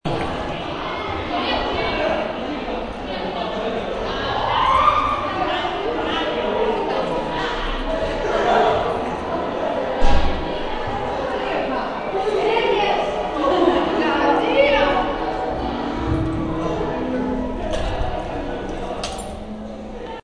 Posnetek zvoka na hodniku šole
hodnik.mp3